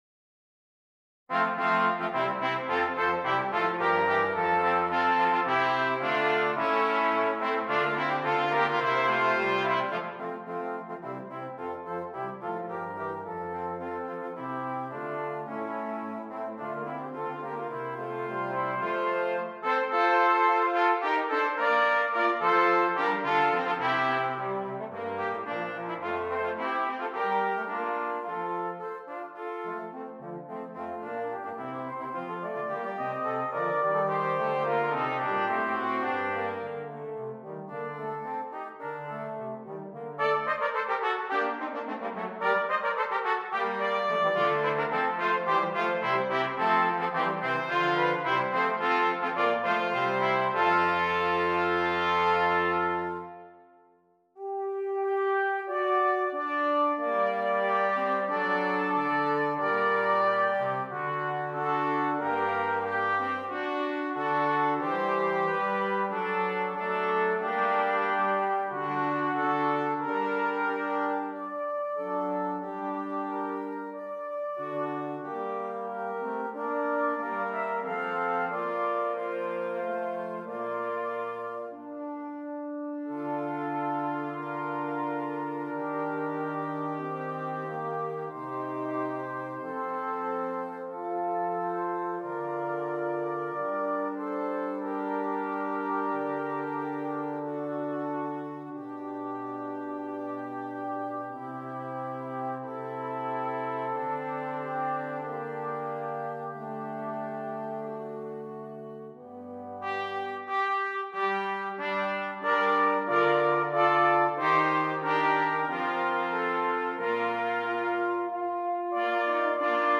Brass Quartet
great collection of famous madrigals for brass quartet